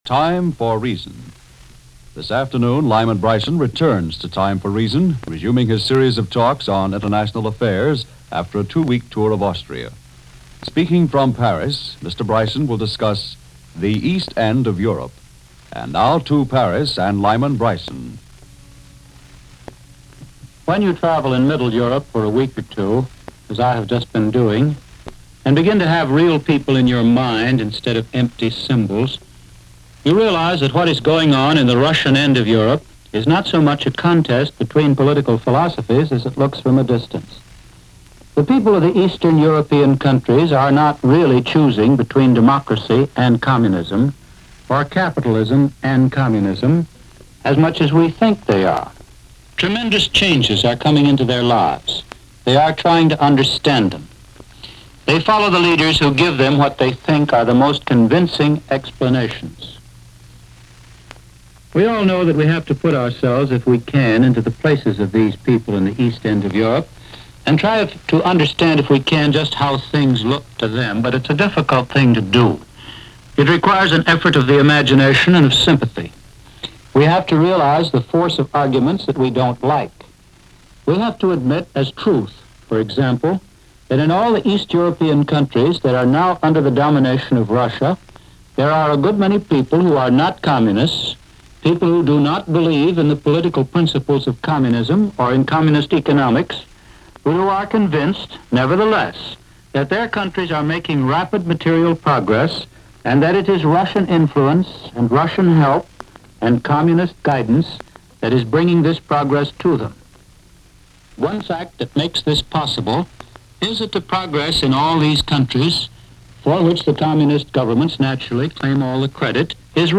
This program, part of the Sunday radio series A Time For Reason, presented by CBS News